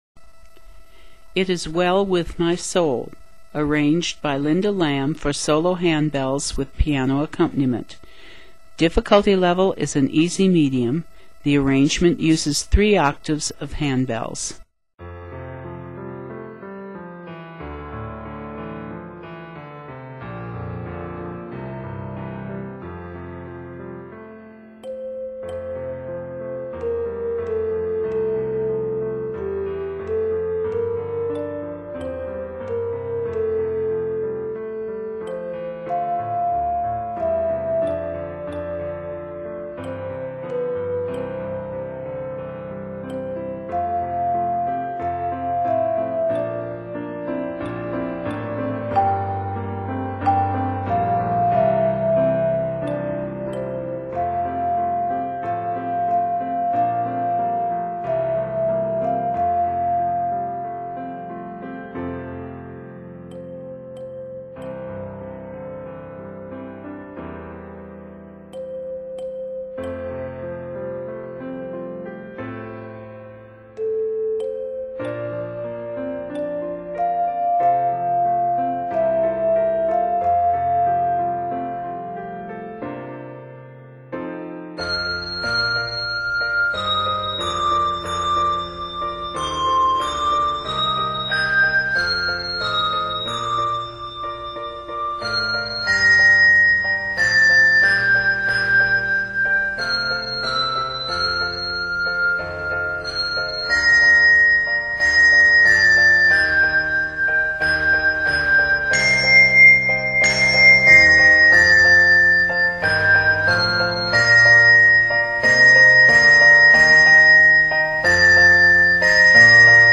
Set in F major and Bb Major, measures total 55.
Solo Ringer
Hymn Tune